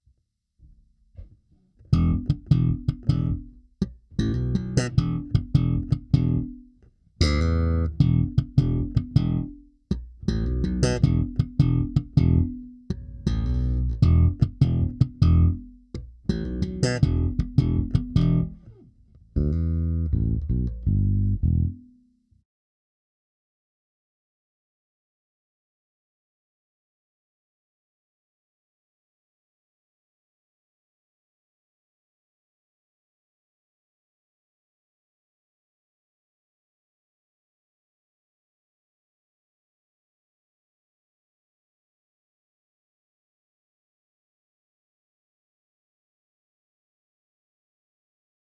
Naskytla se mi příležitost přímého porovnání na mém setupu. Jedná se o 55-02 vs. Stingray Special 4H, stejné stáří strun, hmatník obojí palisandr.